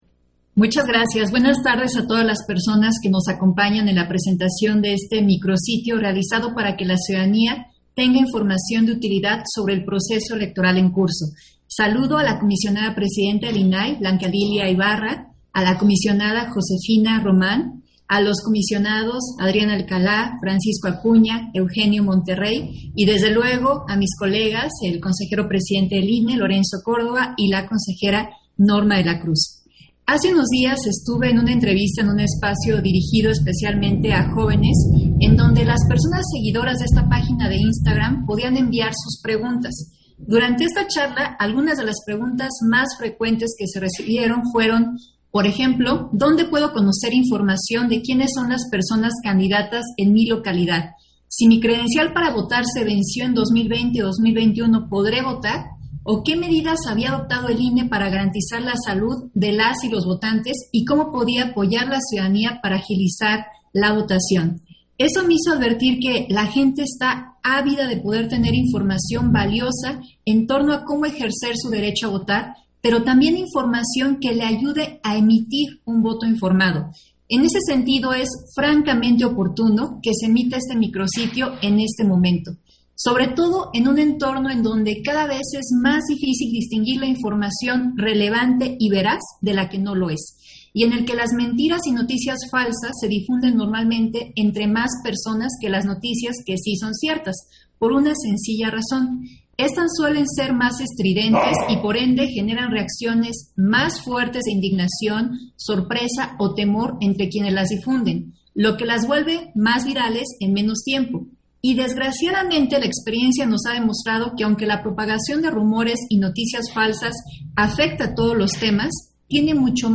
Intervención da Dania Ravel, durante la presentación del micrositio, Información útil sobre el Proceso Electoral 2021